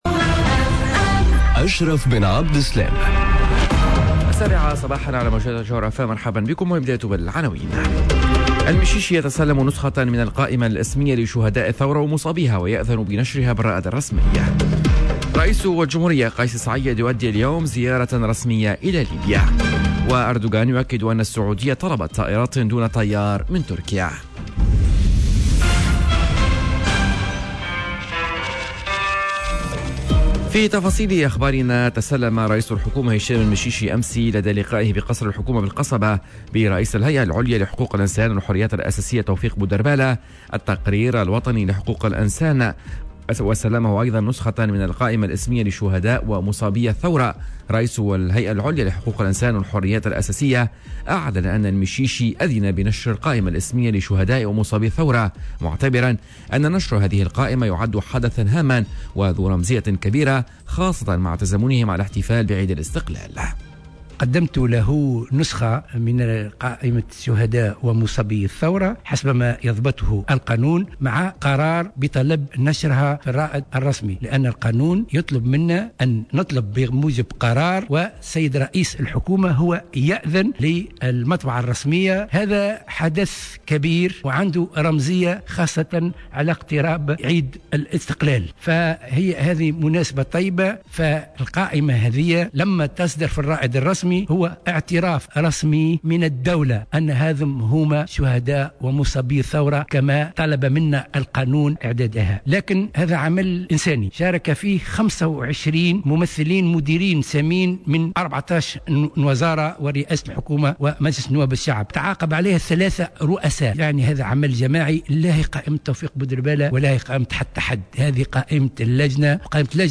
نشرة أخبار السابعة صباحا ليوم الإربعاء 17 مارس 2021